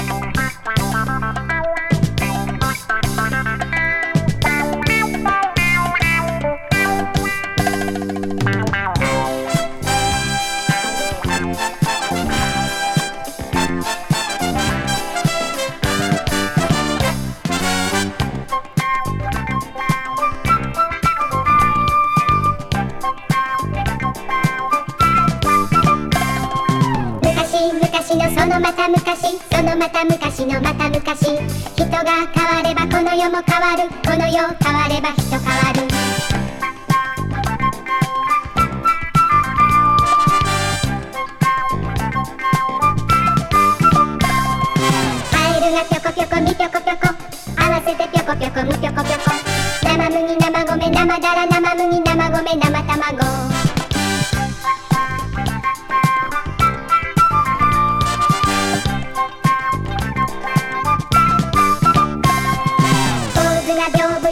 ライト・メロウ・ディスコ